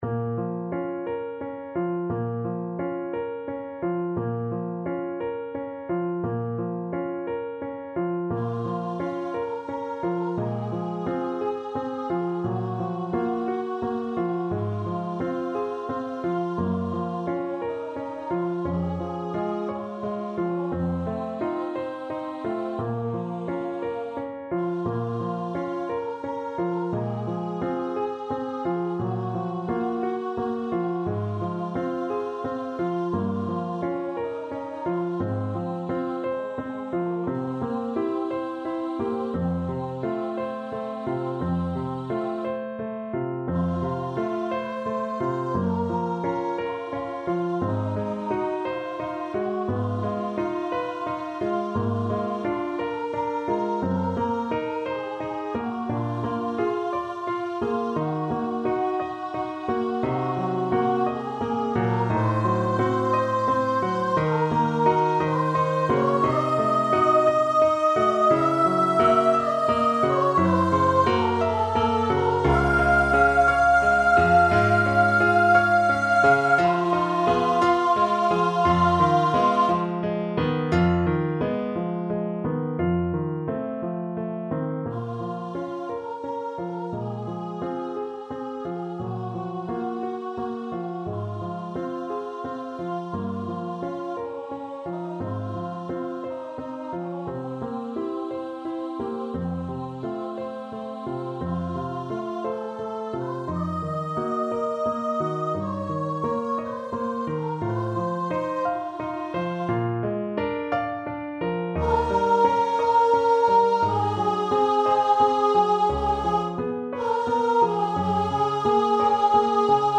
12/8 (View more 12/8 Music)
Andante .=58
Classical (View more Classical Voice Music)